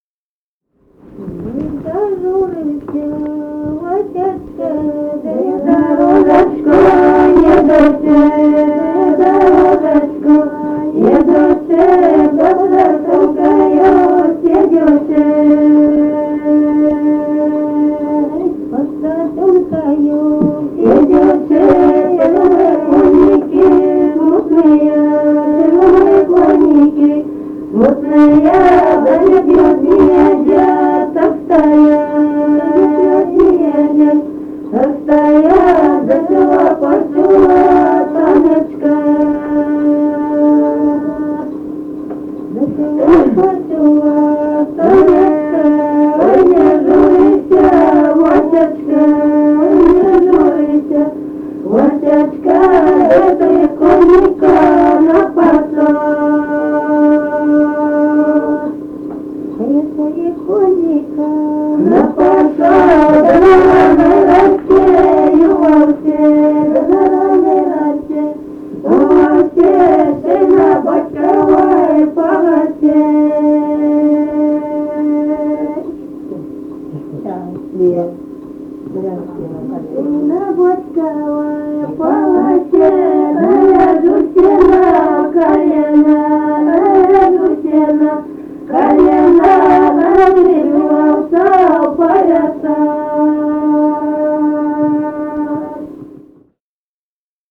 Файл:47 Ой, зажурився Васечка (свадебная) И0866-03а Лобановка.mp3 — Фолк депозитарий